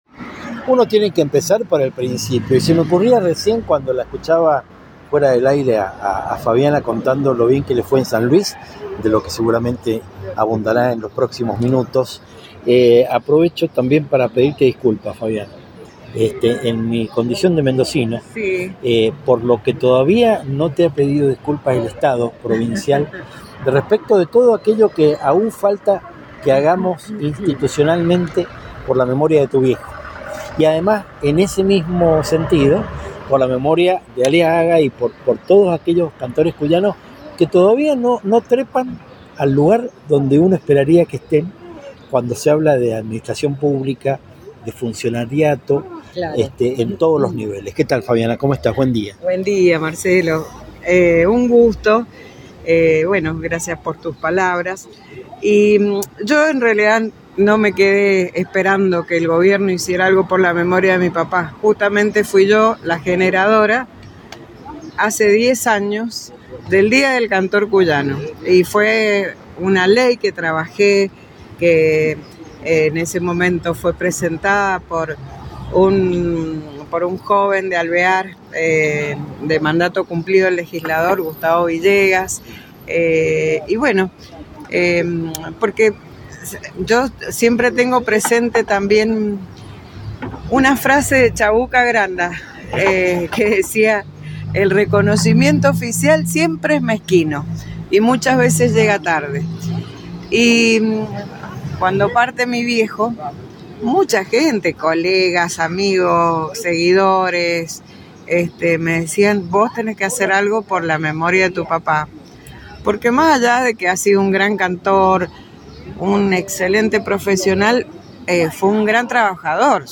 Entrevistas Latinocracia